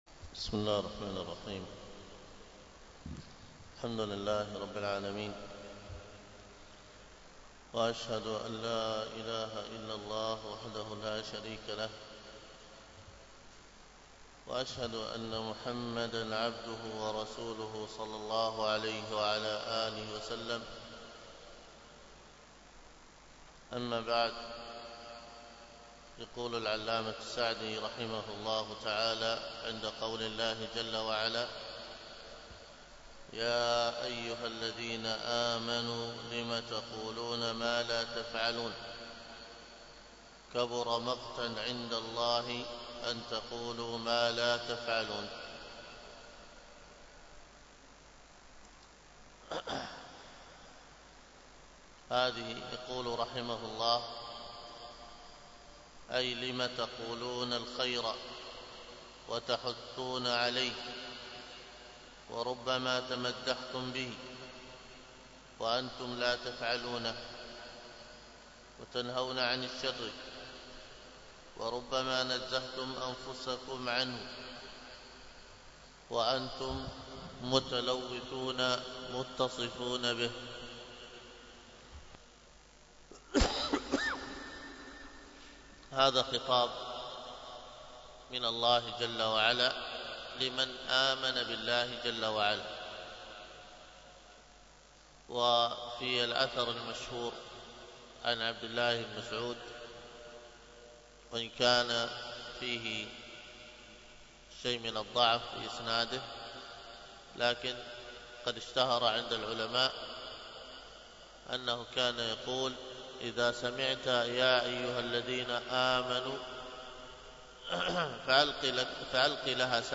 الدرس في تفسير سورة المجادلة من تفسير الجلالين 3